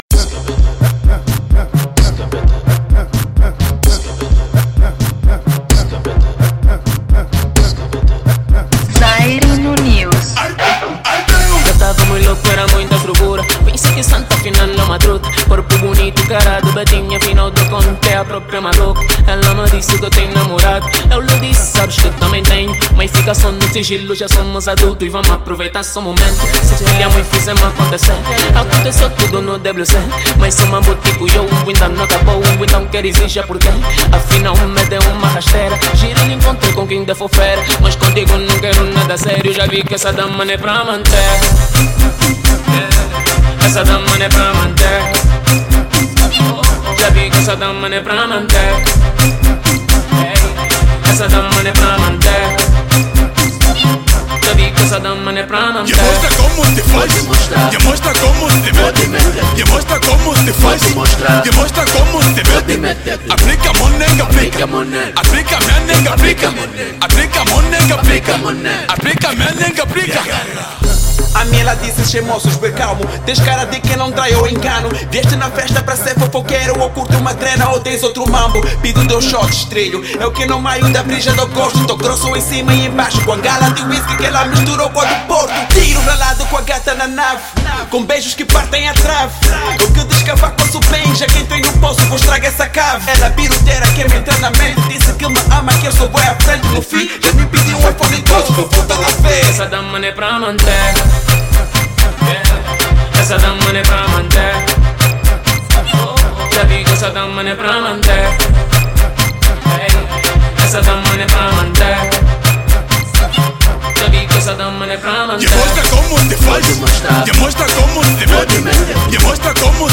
Estilo: Rapduro